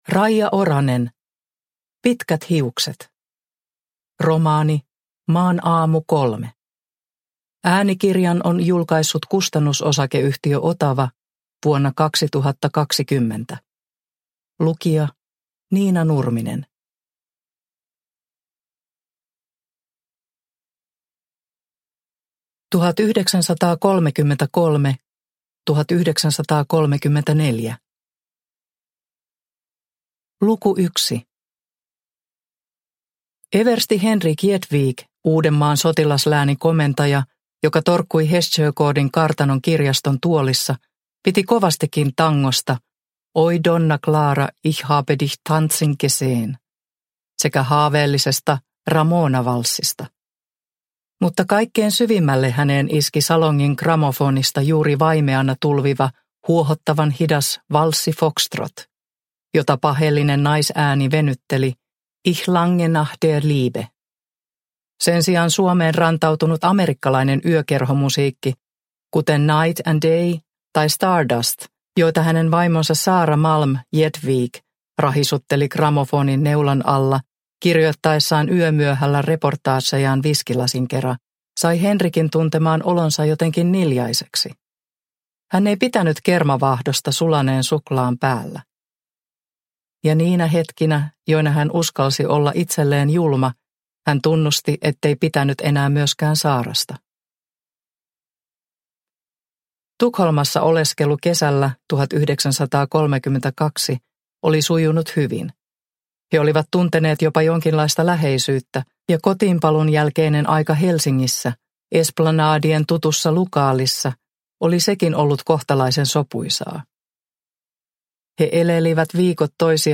Pitkät hiukset – Ljudbok – Laddas ner
Produkttyp: Digitala böcker